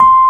RHODES2S C6.wav